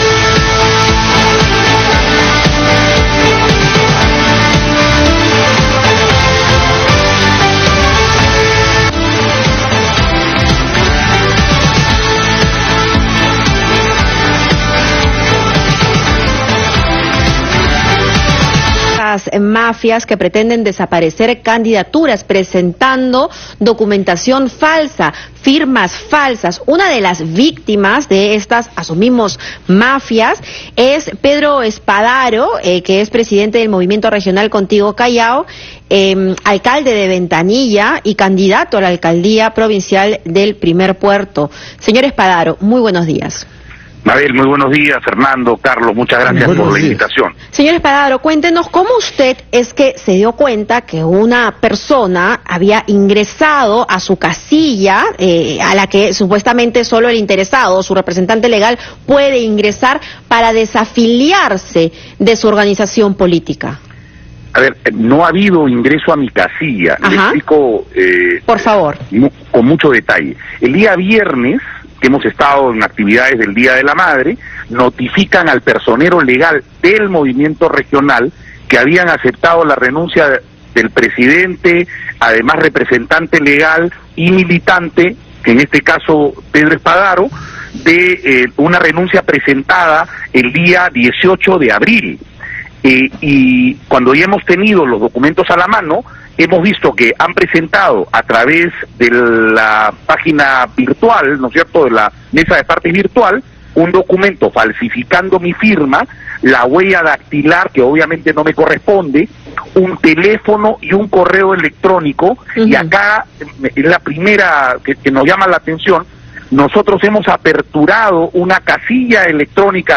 Entrevista al alcalde de Ventanilla y candidato a la alcaldía del Callao, Pedro Spadaro (parte 1)